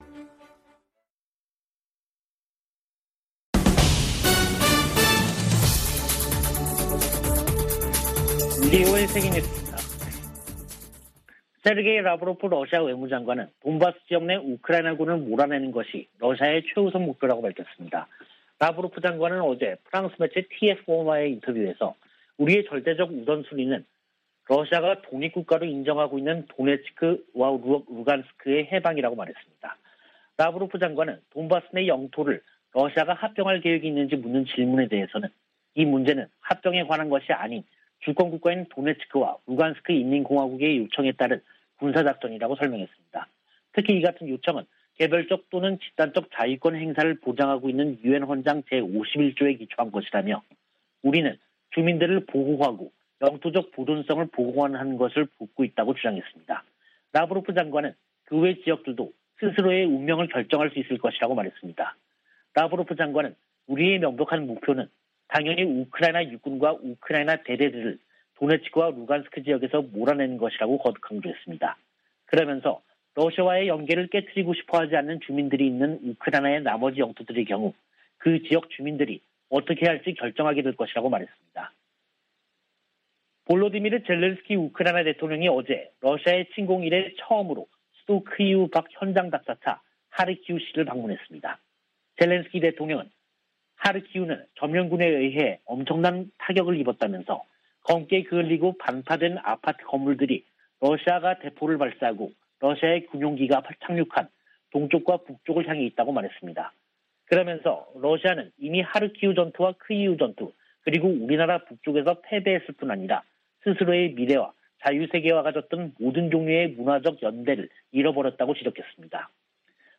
VOA 한국어 간판 뉴스 프로그램 '뉴스 투데이', 2022년 5월 30일 3부 방송입니다. 미 재무부가 북한의 최근 탄도미사일 발사에 대응해 북한 국적자와 러시아 기관들을 추가 제재했습니다. 미한일 외교장관들은 유엔 안보리가 새 대북 결의안 채택에 실패한 데 유감을 나타내고 3국 협력은 물론 국제사회와의 조율을 강화하겠다고 밝혔습니다. 미 국방부는 북한이 계속 불안정을 야기한다면 군사적 관점에서 적절한 대응을 위한 방안을 찾을 것이라고 밝혔습니다.